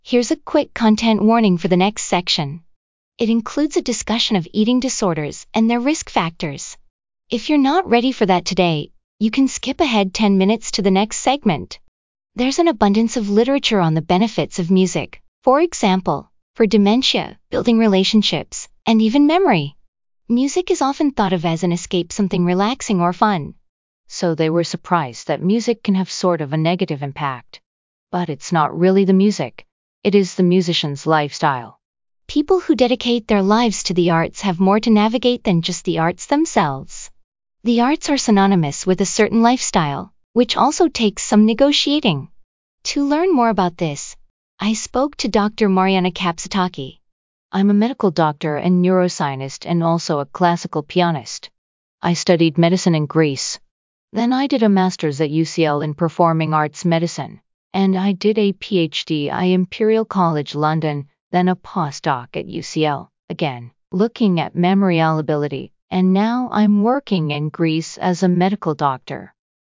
summarize-spoken-text-interview-2.mp3